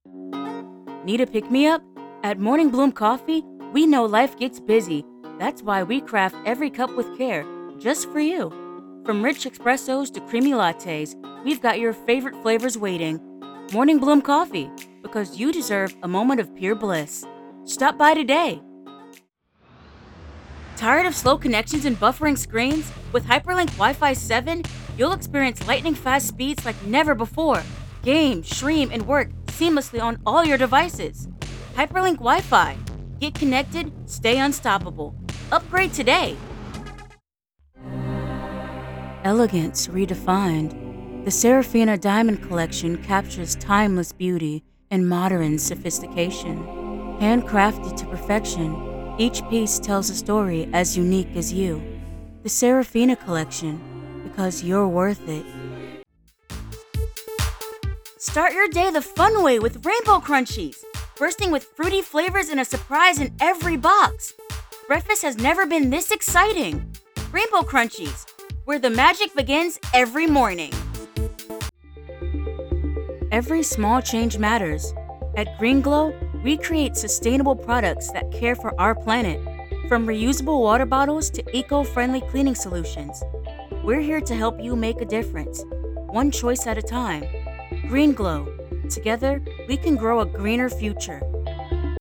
Commercial
Broadcast-ready home studio.
Commercial Reel.wav